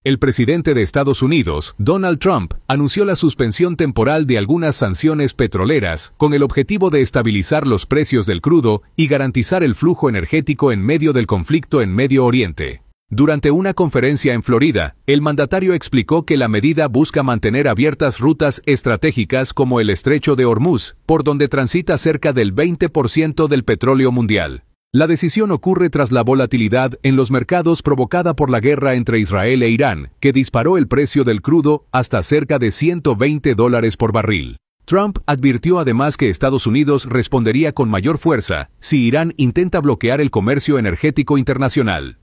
Durante una conferencia en Florida, el mandatario explicó que la medida busca mantener abiertas rutas estratégicas como el estrecho de Ormuz, por donde transita cerca del 20 por ciento del petróleo mundial.